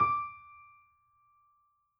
piano_074.wav